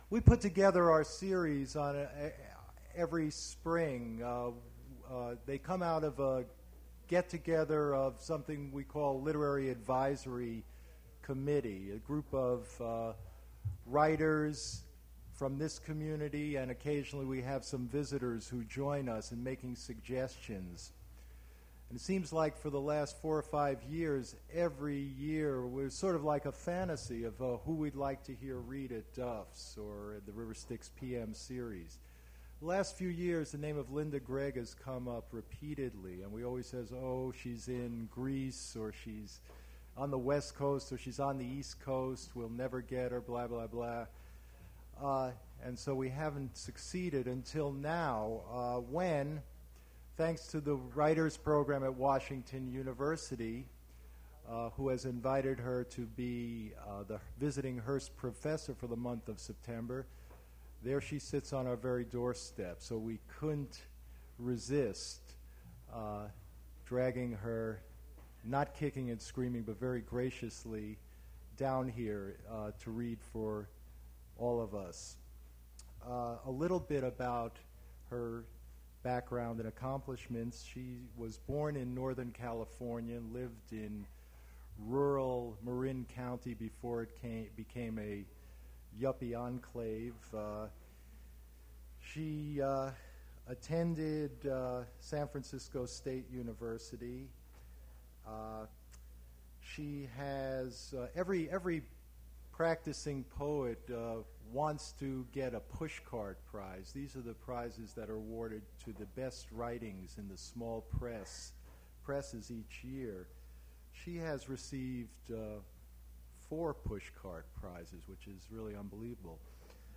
Poetry reading featuring Linda Gregg
Attributes Attribute Name Values Description Linda Gregg poetry reading at Duff's Restaurant.
mp3 edited access file was created from unedited access file which was sourced from preservation WAV file that was generated from original audio cassette.
Cut about 1 min of white noise at beginning; cut remarks at end